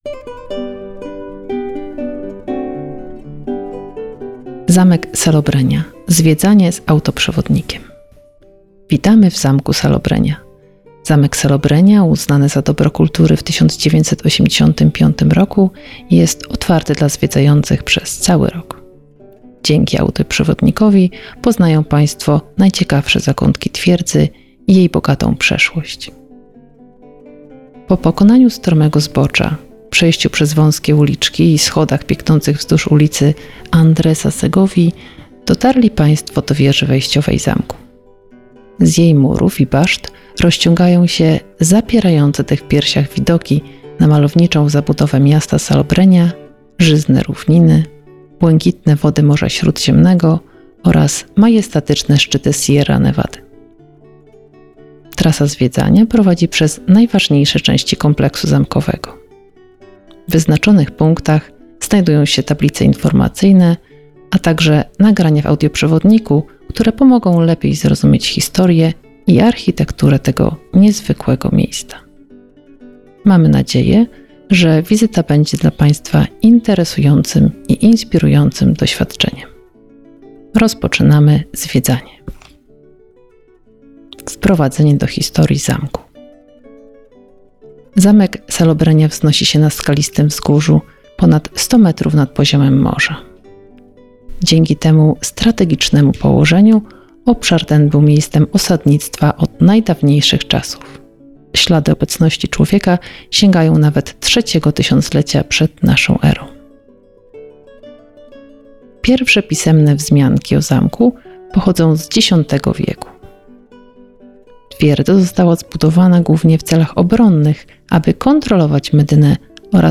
Zamek Salobreña – Zwiedzanie z audioprzewodnikiem